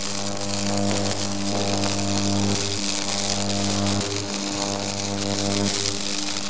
electric_sparks_lightning_loop4.wav